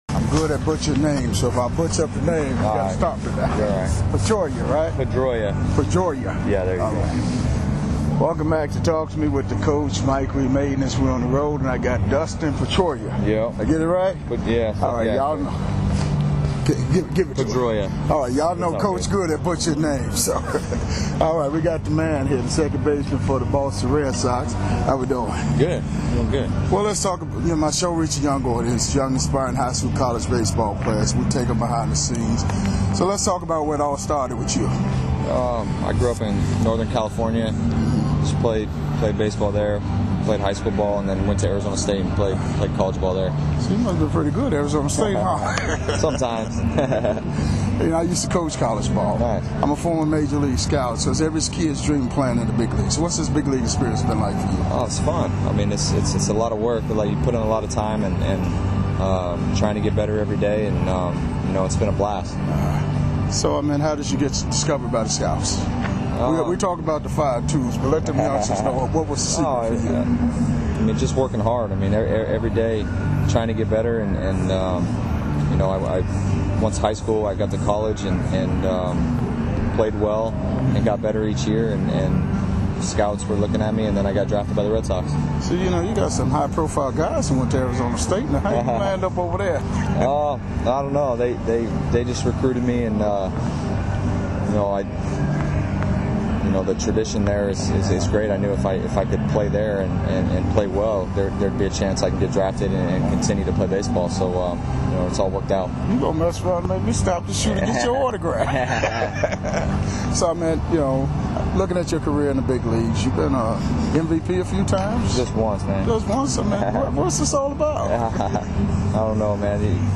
Classic MLB interviews